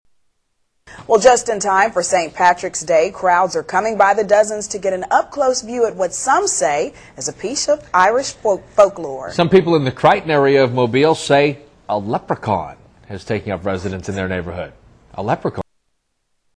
A Leprechaun News report